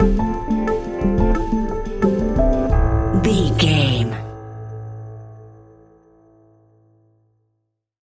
Ionian/Major
techno
trance
synthesizer
synthwave